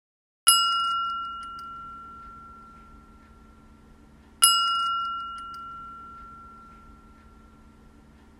Zvonček so stopkou – výška 12,5 cm
Zvuk zvončeka previbruje priestor.
Materiál: mosadz
zvoncek-so-stopkou-1.mp3